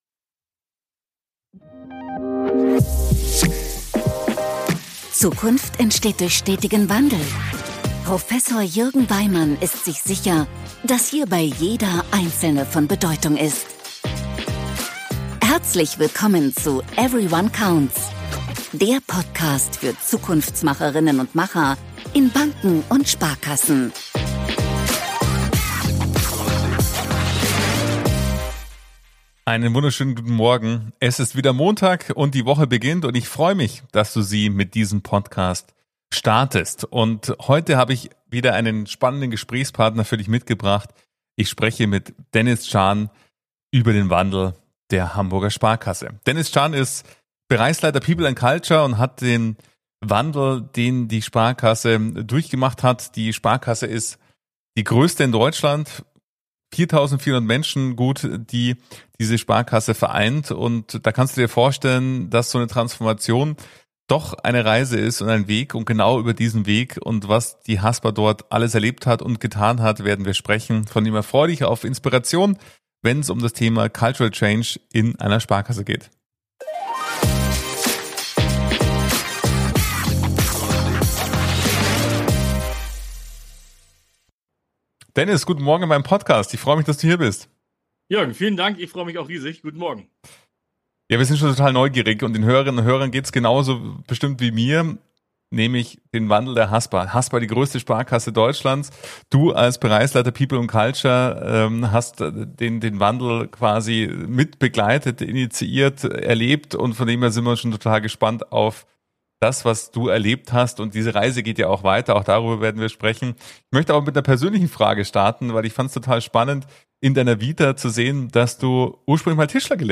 Wir haben es MAHLZEIT getauft, da es eine interaktive Mittagspause ist.